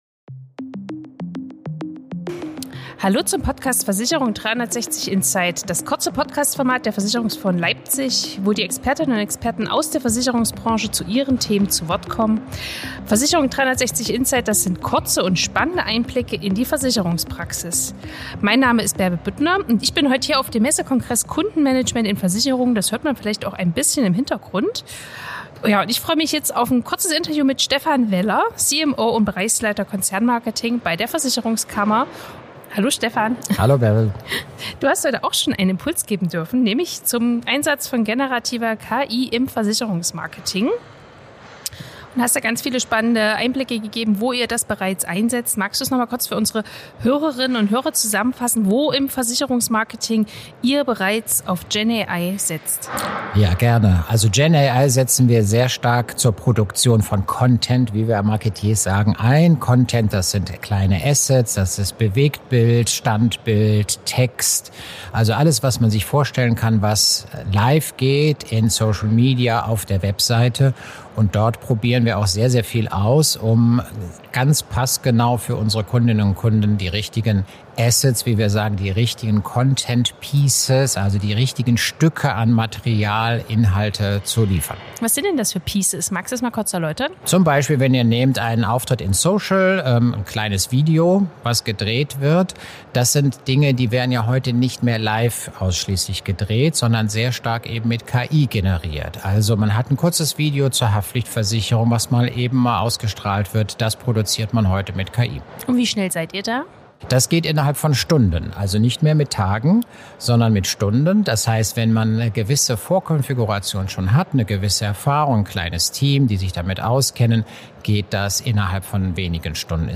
Der Podcast Versicherung 360 bringt Branchenexpertinnen und